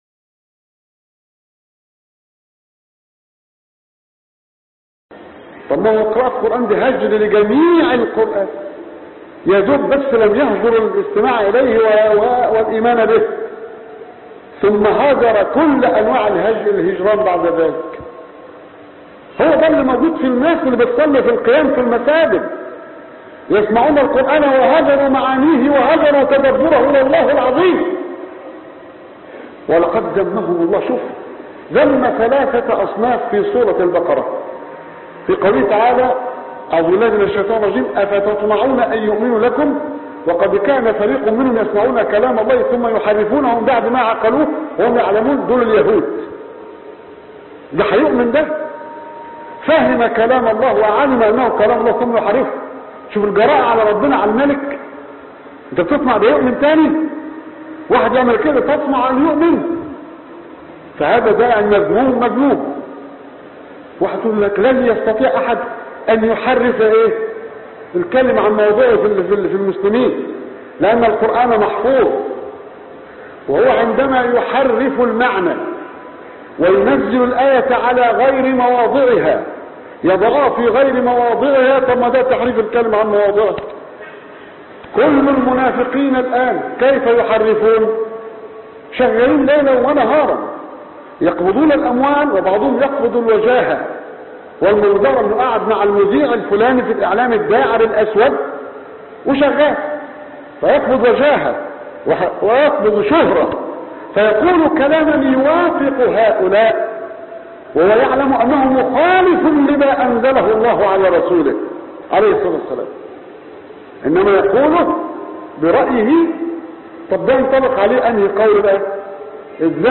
هجر القرآن في صلاة القيام وختمات التلاوة في رمضان _ خطب الجمعة